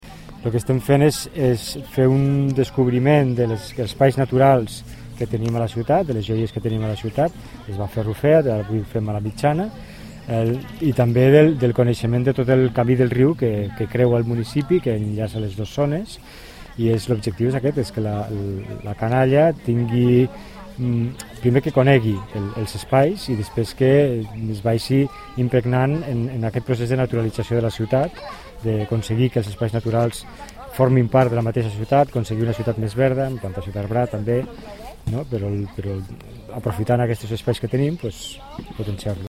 Tall de veu S. Talamonte.
tall-de-veu-sergi-talamonte